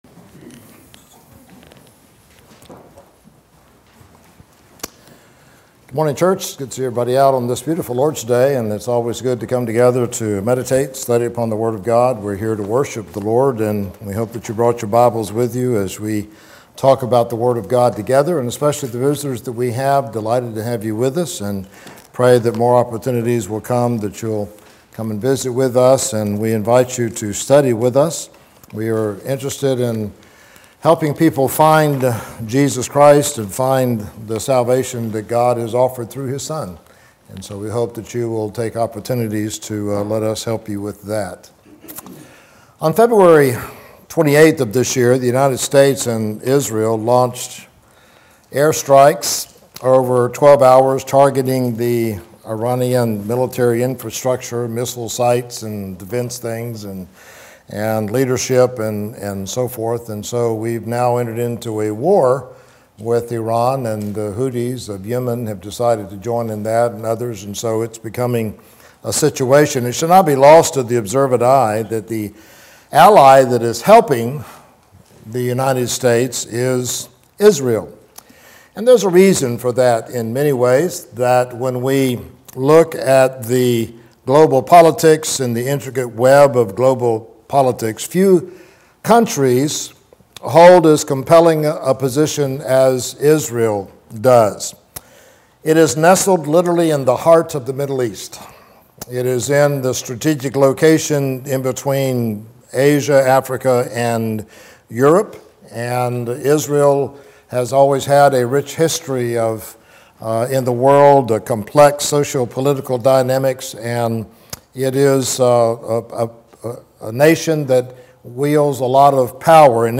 Sunday AM Sermon – Prayer For Israel